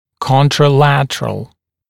[ˌkɔntrə’lætərəl][ˌконтрэ’лэтэрэл]контралатеральный, противоположный, располагающийся на противоположной стороне